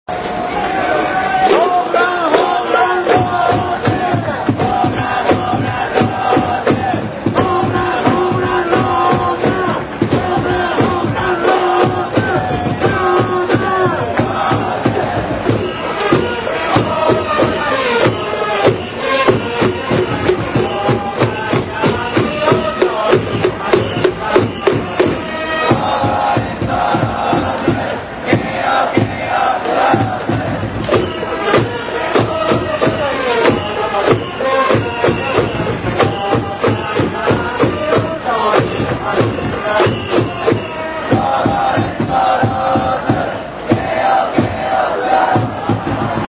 ２００３年応援歌